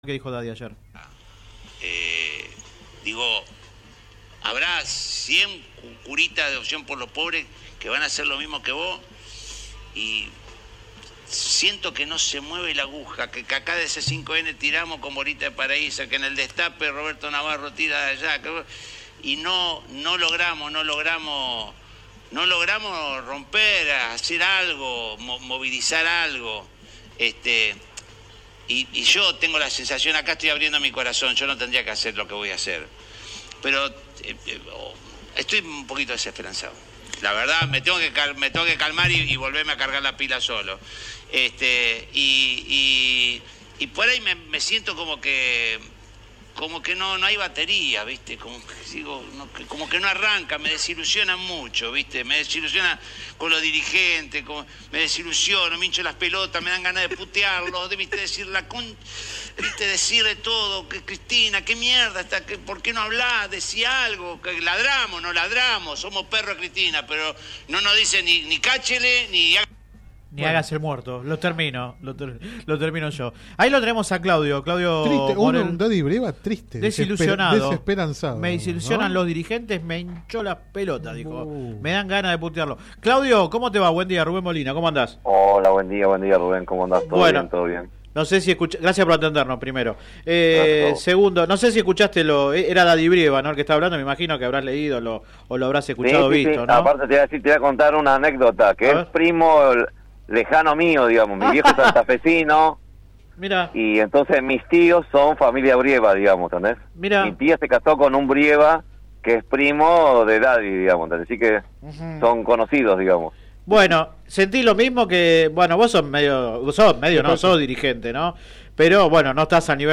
Click acá entrevista radial